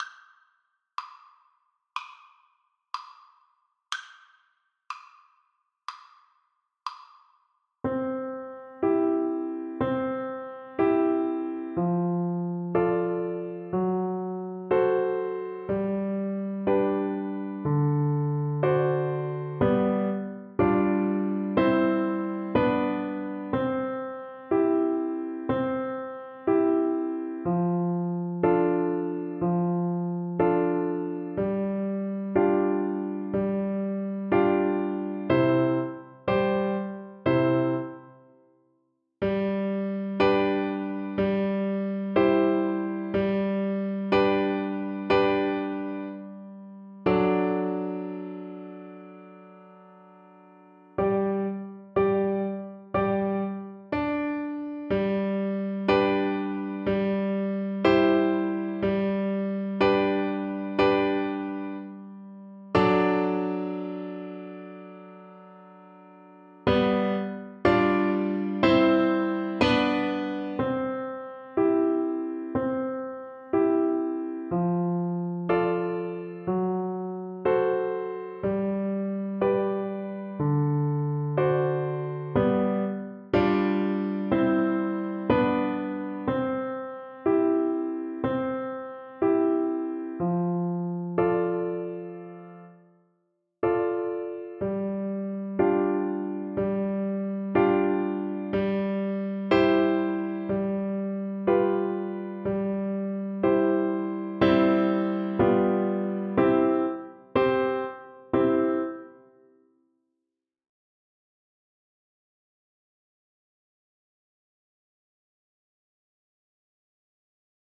Oboe version
C major (Sounding Pitch) (View more C major Music for Oboe )
Andante = c. 90
Classical (View more Classical Oboe Music)